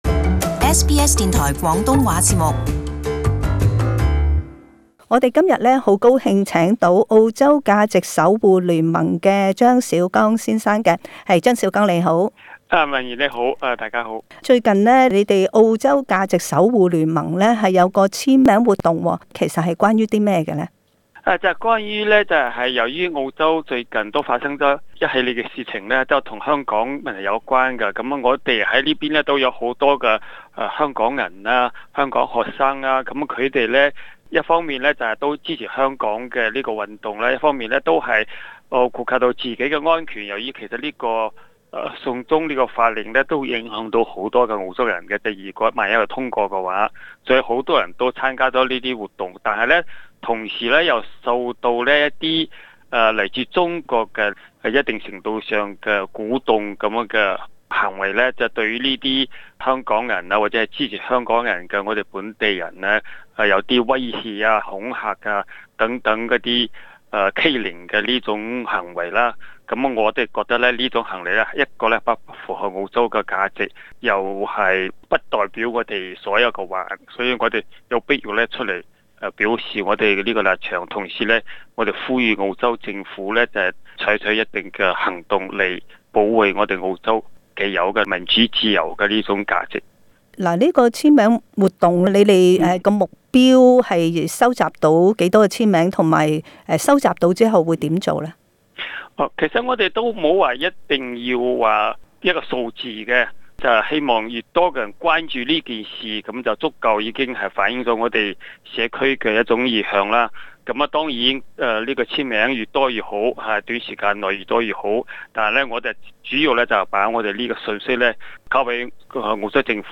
【社區專訪】支持香港爭取民主簽名活動